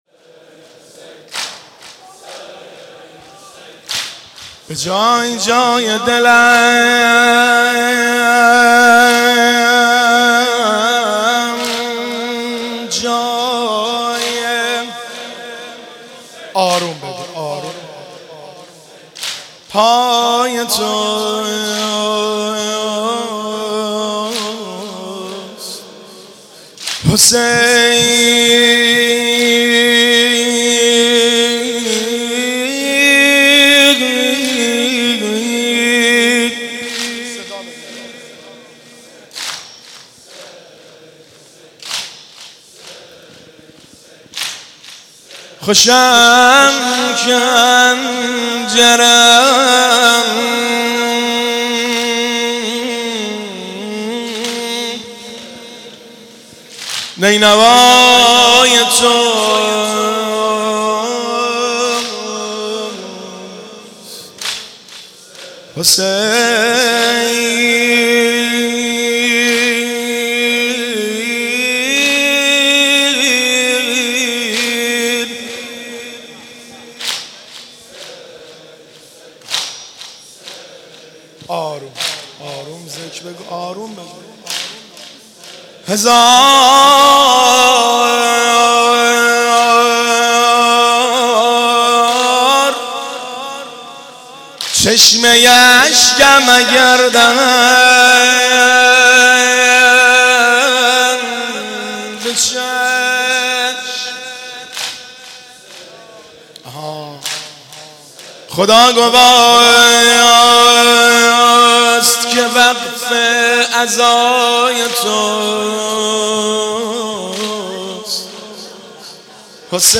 صوت مراسم شب چهارم محرم ۱۴۳۷ هیئت غریب مدینه امیرکلا ذیلاً می‌آید: